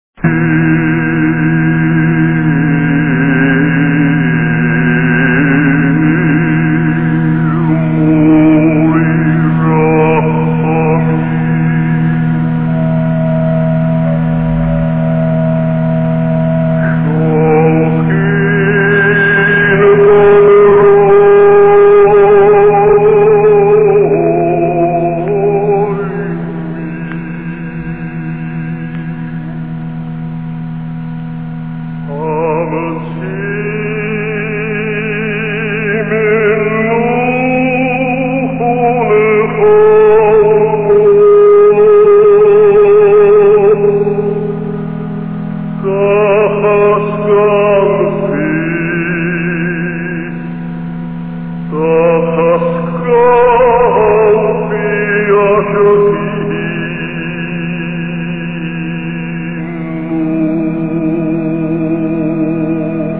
Másféle halálról szól a Kél mólé ráchámim, amelynek hagyományos dallama az Örökkévalóhoz könyörög.